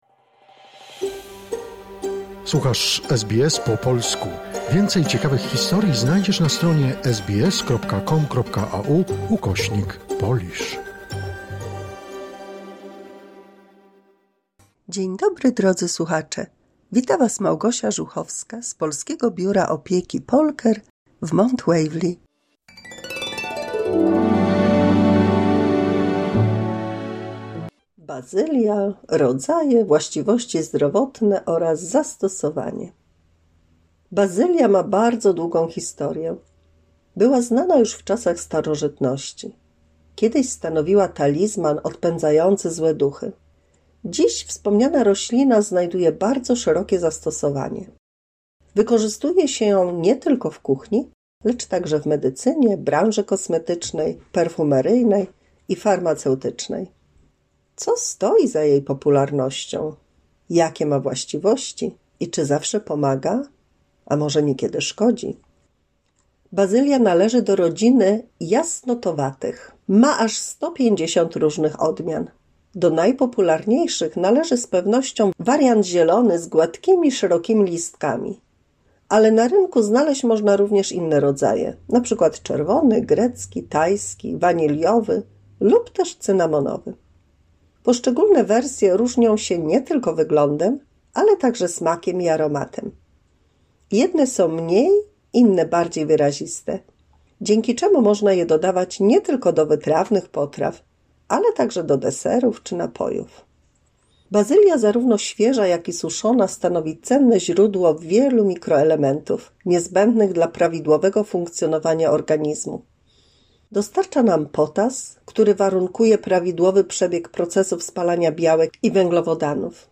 W 221 mini-słuchowisku dla polskich seniorów ciekawe informacje o Bazylii, jej zastosowaniu i właściwościach zdrowotnych...